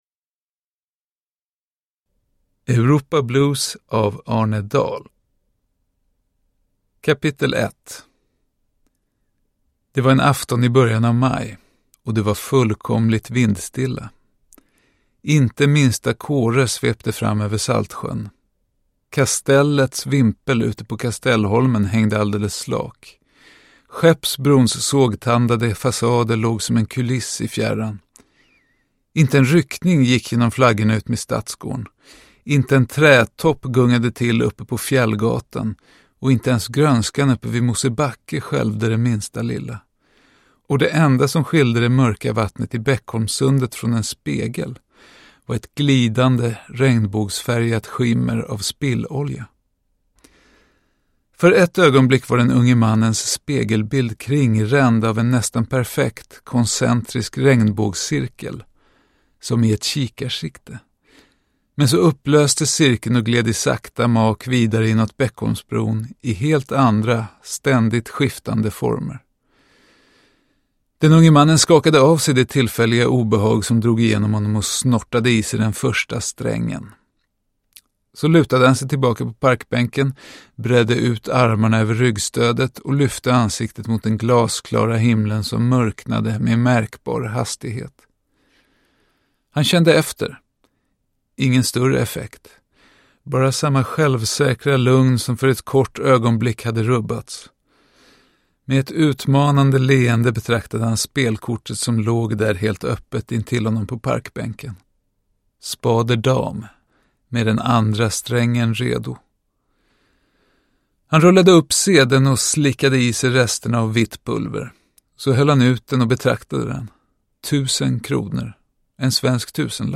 Europa Blues – Ljudbok – Laddas ner
Uppläsare: Arne Dahl